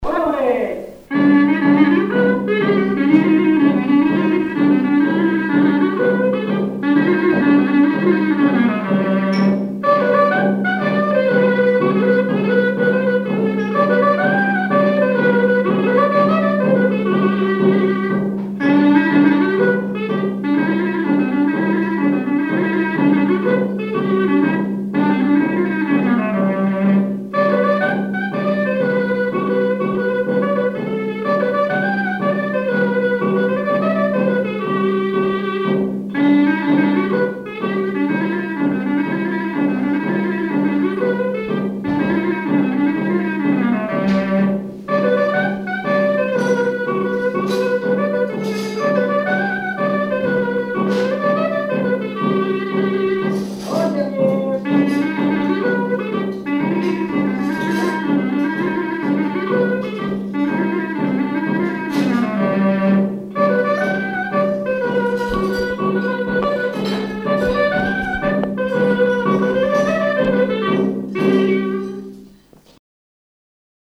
danse : branle : avant-deux
Pièce musicale inédite